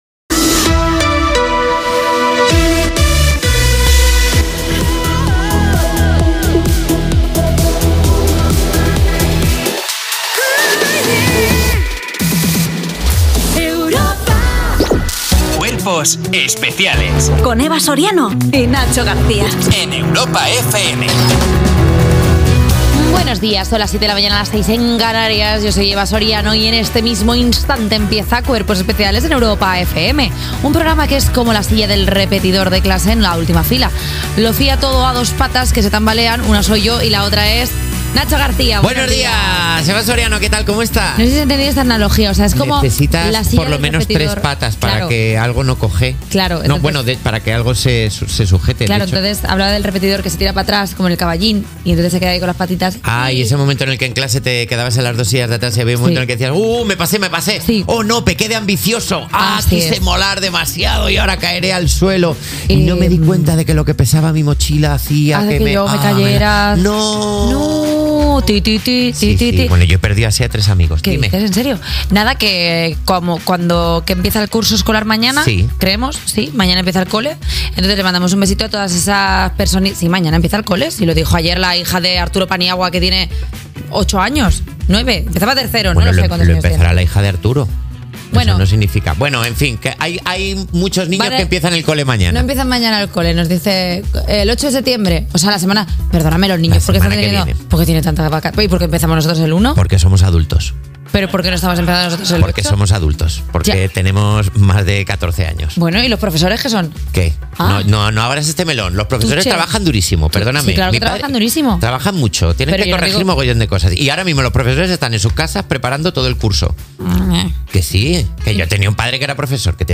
Indicatiu de la ràdio, careta, presentació del programa amb el diàleg dels presentadors sobre l'inici del curs escolar, sumari de continguts
Entreteniment